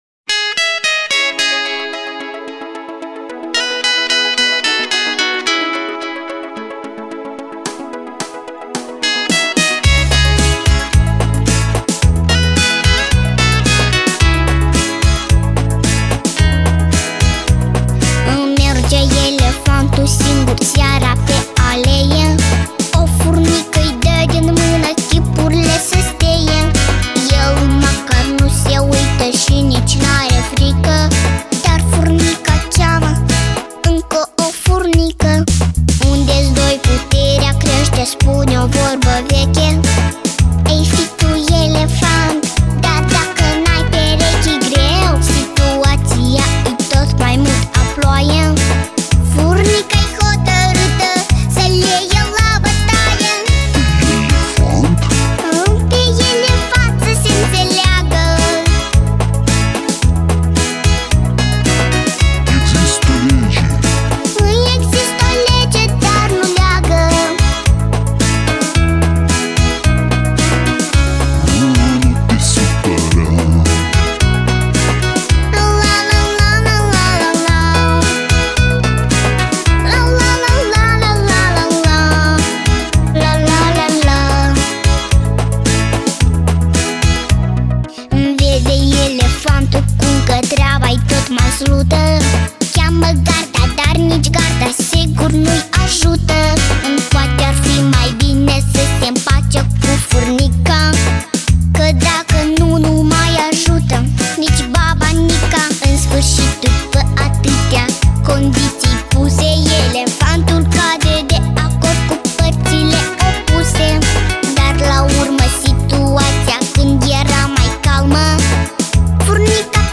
稚嫩的嗓音散发出天真无邪的甜甜味道，